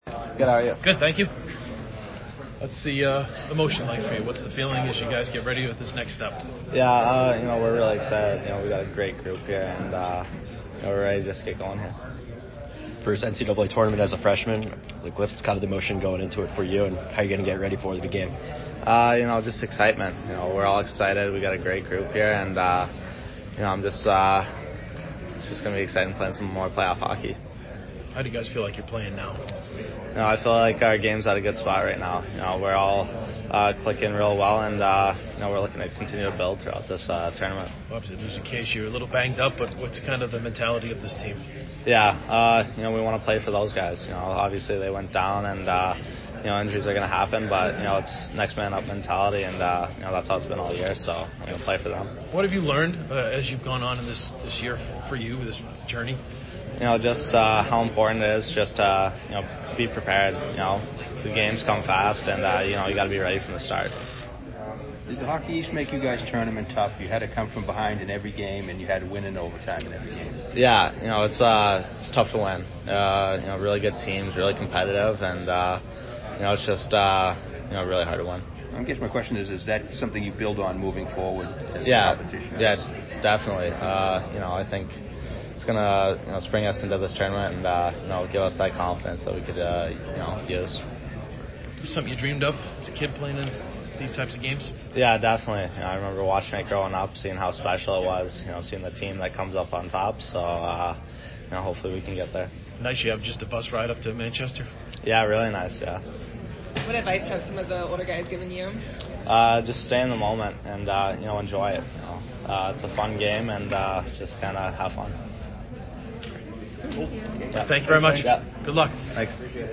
NCAA Selection Show Interview